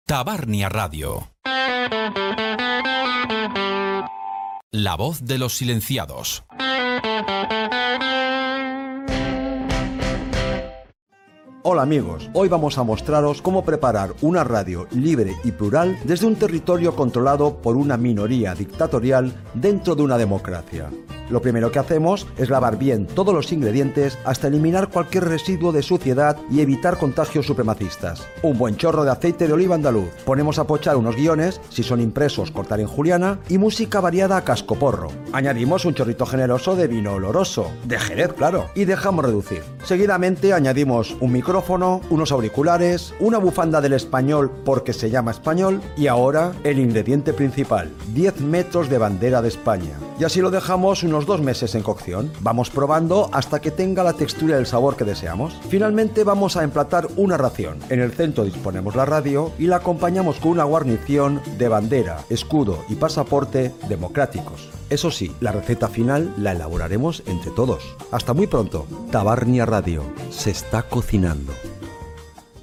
Comentari satíric sobre com s'està cuinant Tabarnia Radio